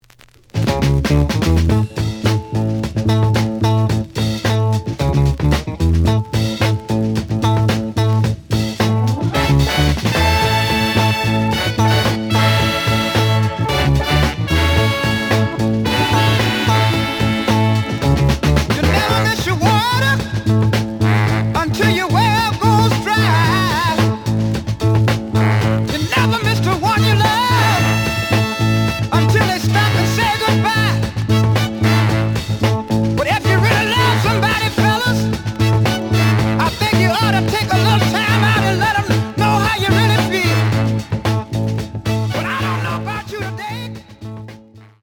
The audio sample is recorded from the actual item.
●Genre: Soul, 60's Soul
Slight noise on A side.)